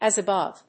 アクセントas abóve